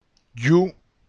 The sounds of flat syllables using the letters LL and Y have no difference to the ear. That is, both letters form syllables that sound the same:
yu-llu.mp3